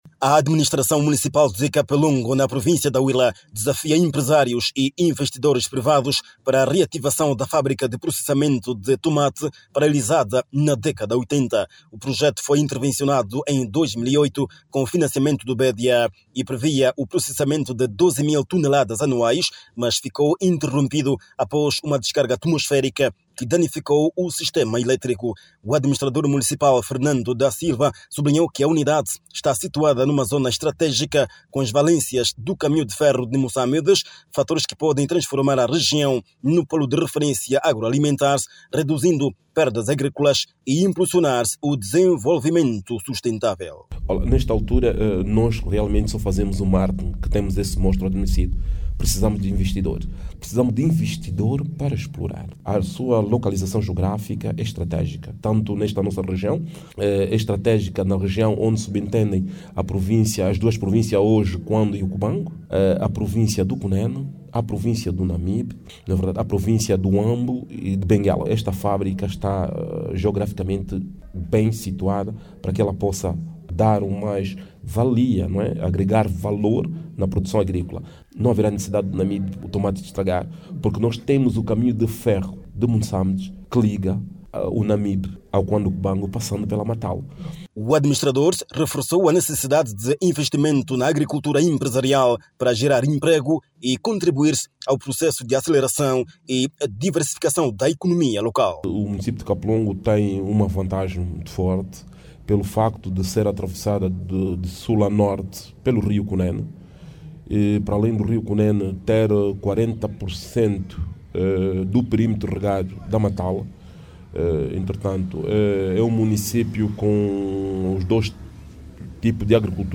HUILA-CAPELONGO-FABRICA-DE-TOMATE-13HRS.mp3